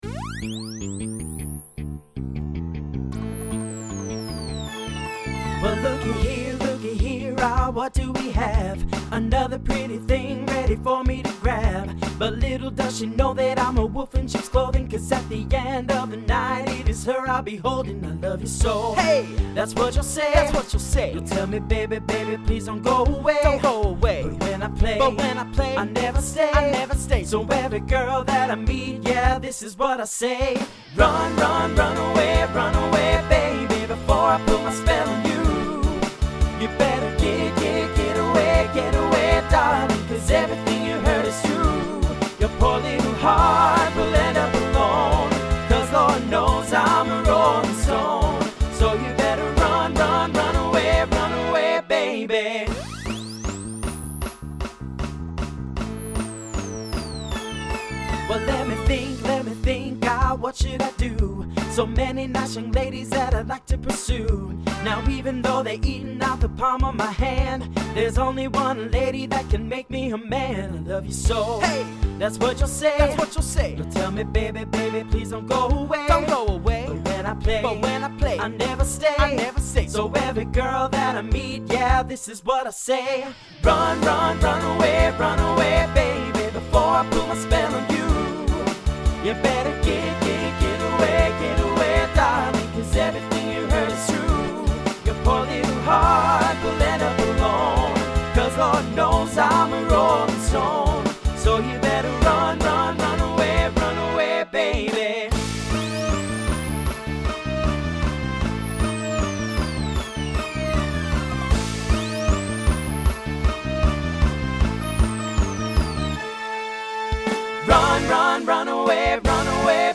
SSA , TTB Instrumental combo Genre Pop/Dance